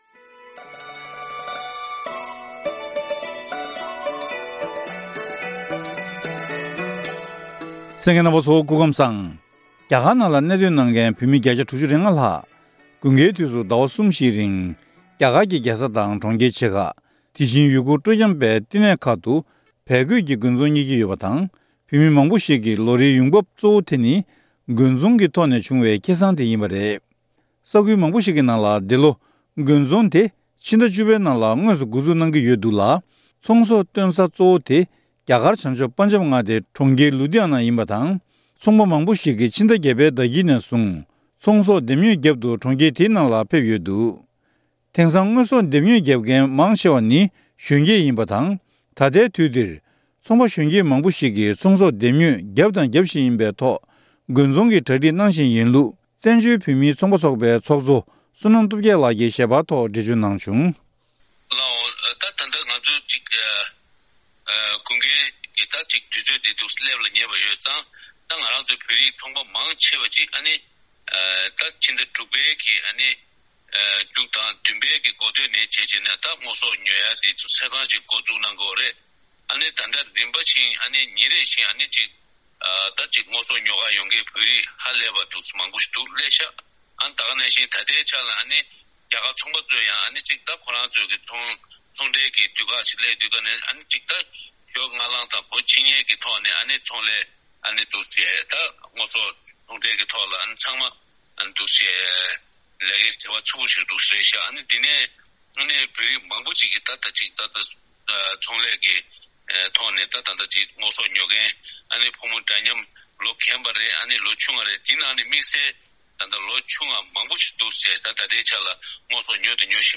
གནས་འདྲི་ཞུས་ནས་ཕྱོགས་སྒྲིགས་ཞུས་པ་ཞིག་གསན་རོགས་གནང་།